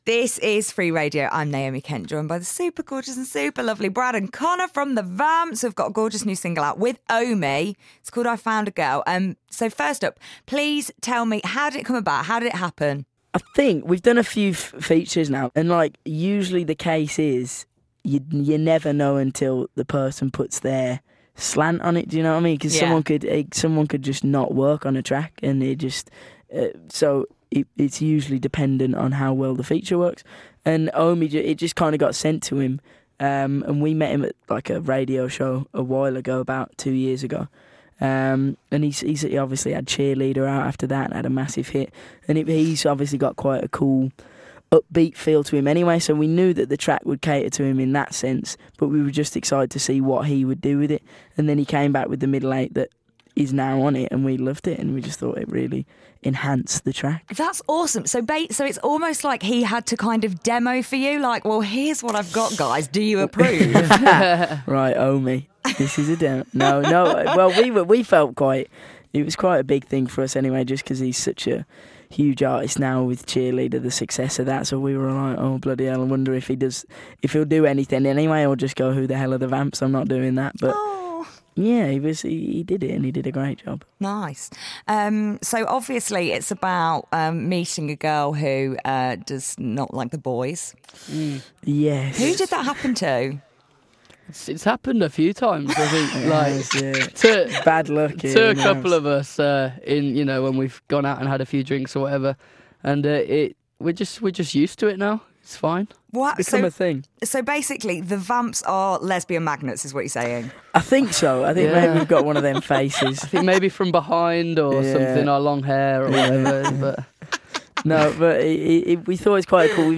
The Vamps Interview